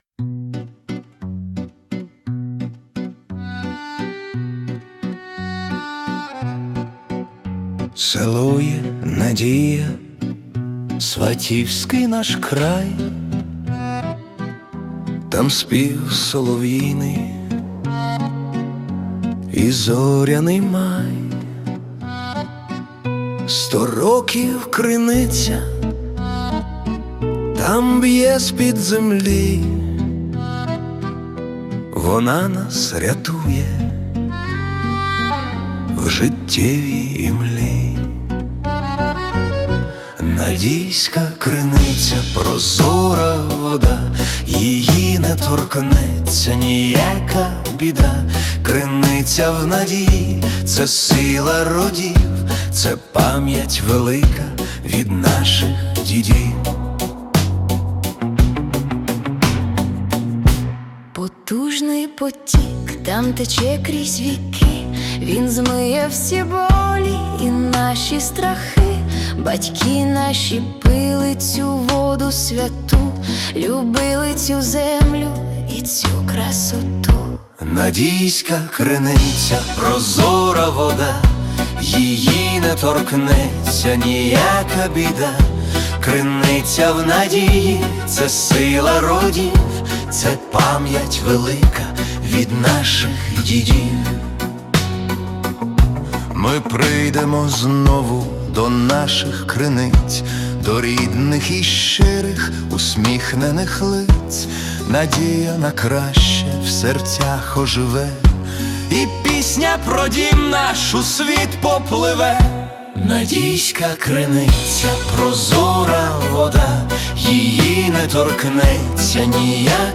🎵 Жанр: Folk-Pop / Nostalgic